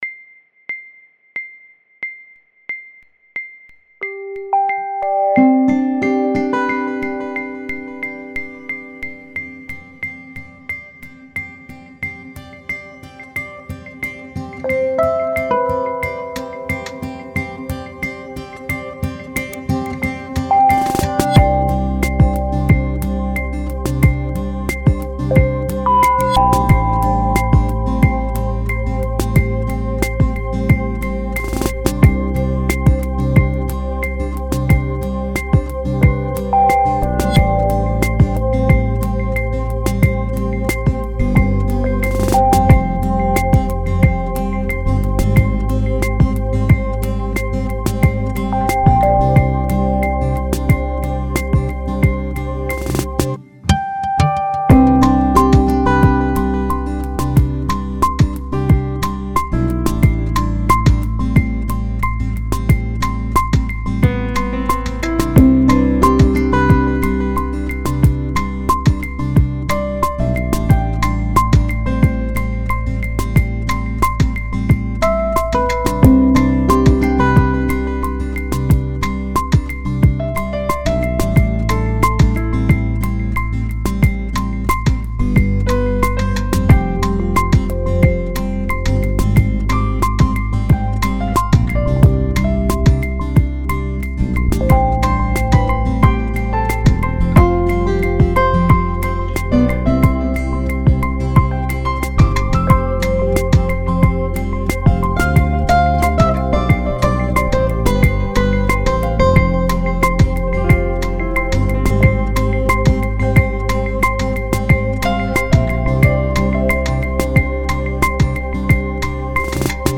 Meditation1.mp3